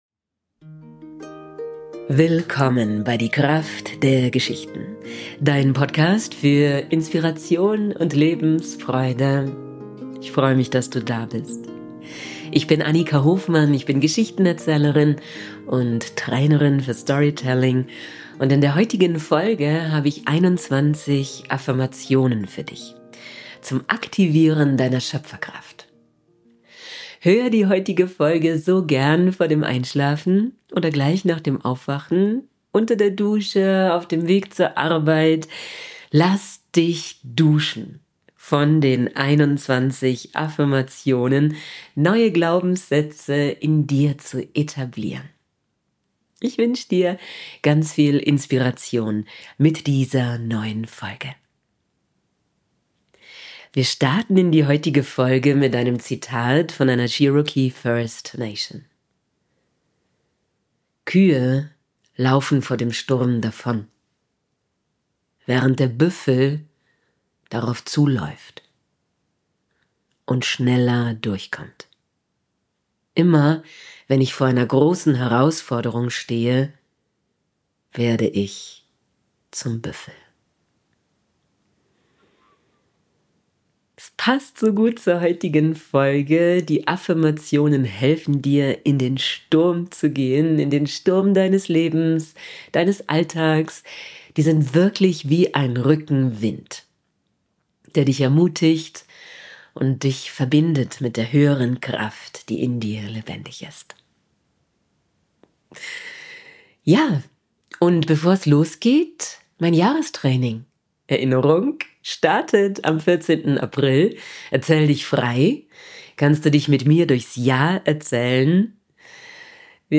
Heute habe ich 21 Affirmationen für Dich eingesprochen zum